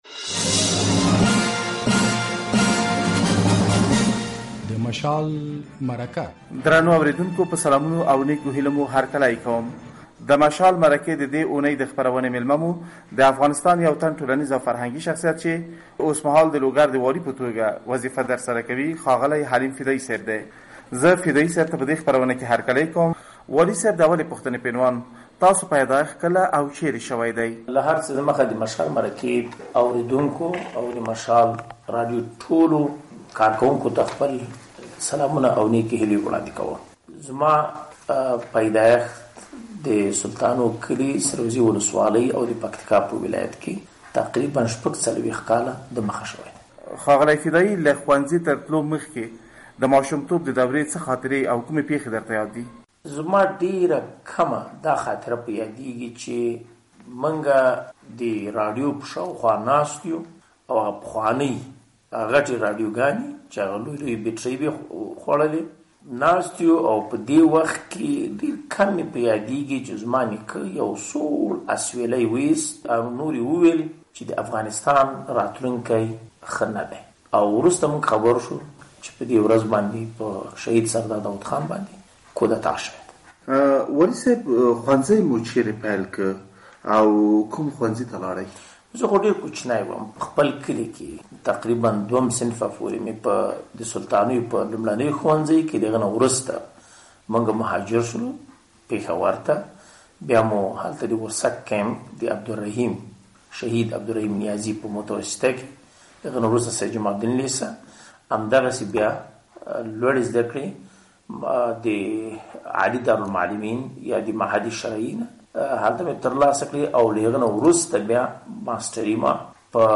له حلیم فدايي سره د مشال ځانګړې مرکه
د مشال مرکې د دې اونۍ میلمه مو د لوګر والي حلیم فدايي دی. نوموړی د حکومتي مسولیت ترڅنګ د یاد وړ فرهنګي شخصیت هم دی.